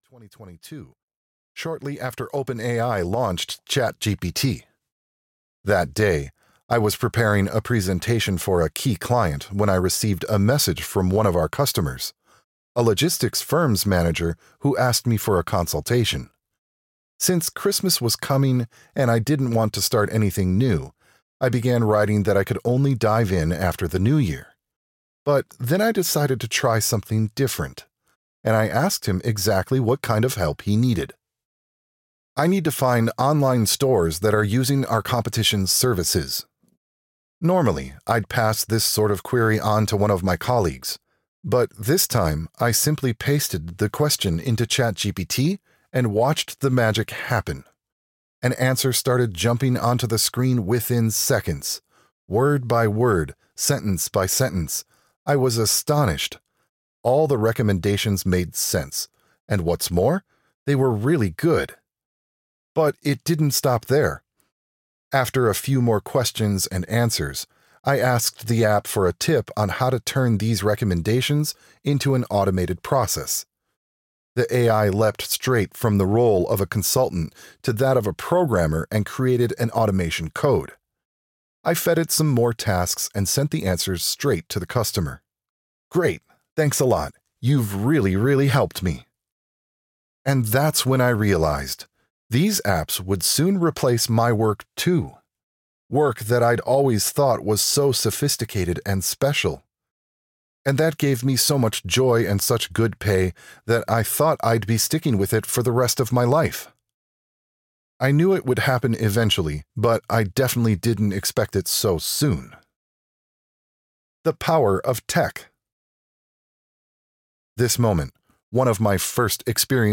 The Future of Nowork audiokniha
Ukázka z knihy